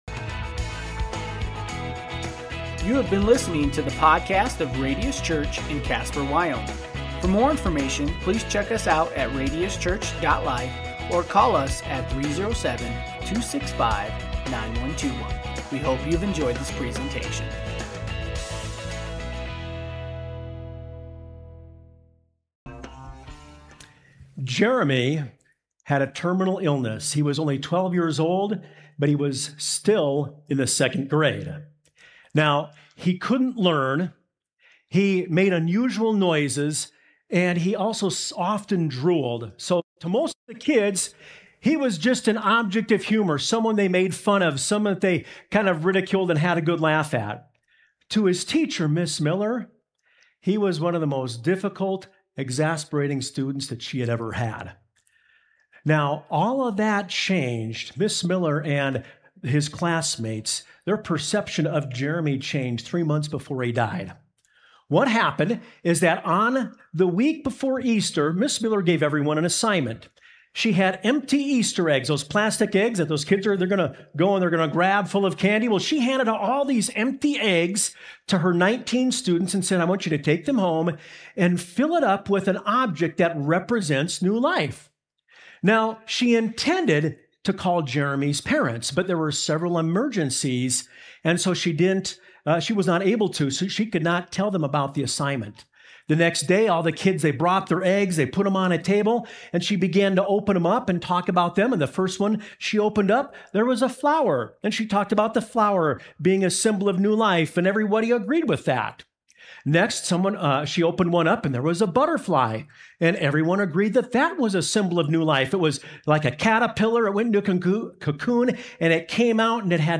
Sermons | Radius Church